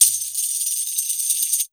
152JAMTAMB-L.wav